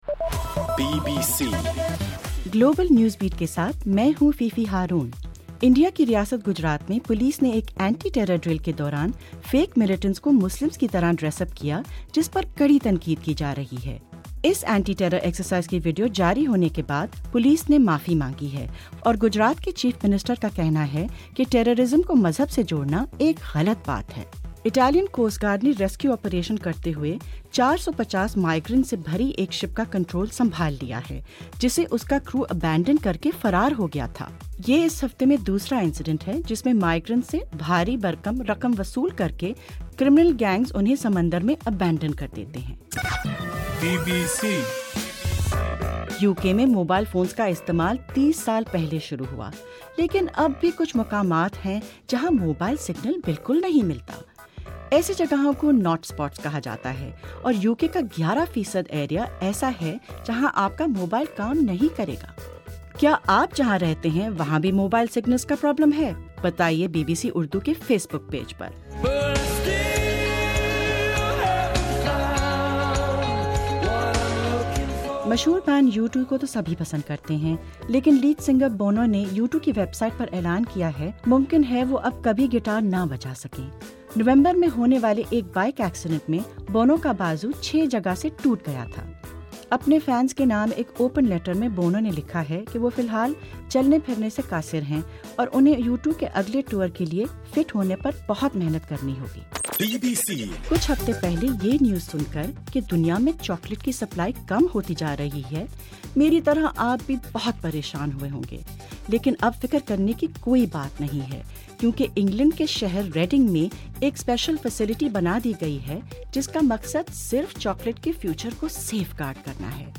جنوری 02: رات 9 بجے کا گلوبل نیوز بیٹ بُلیٹن